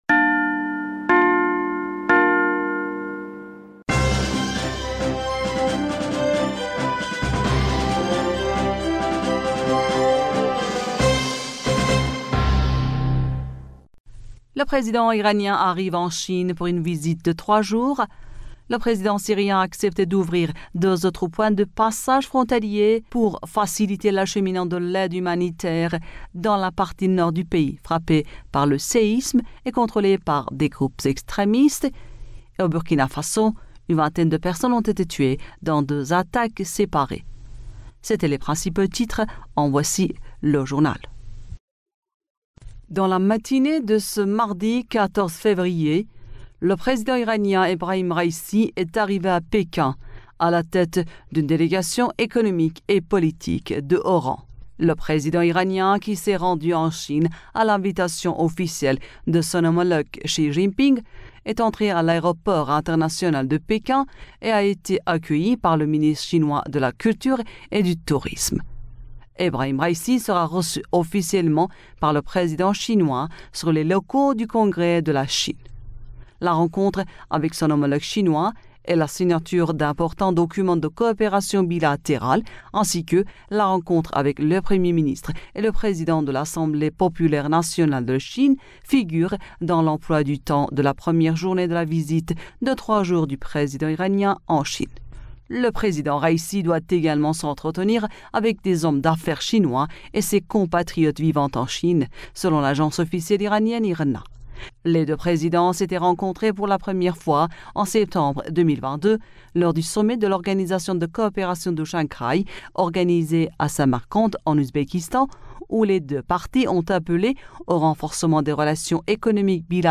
Bulletin d'information du 14 Février